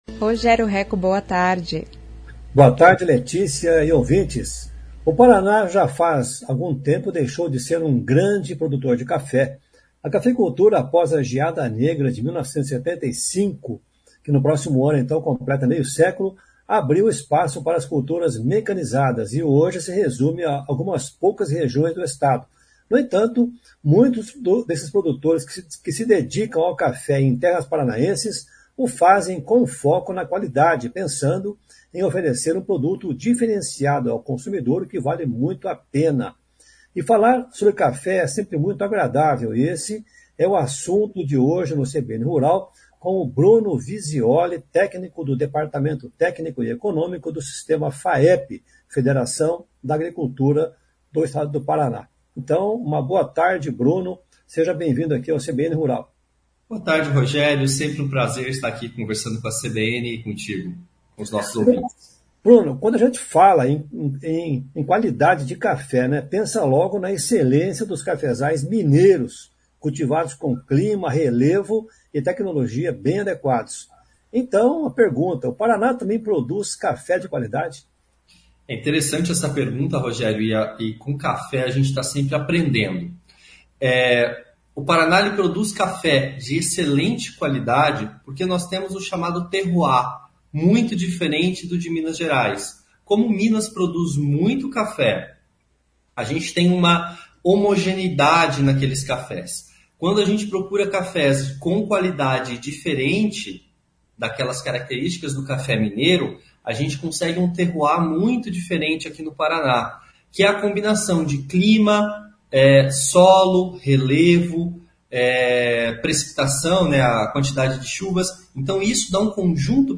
conversa com o técnico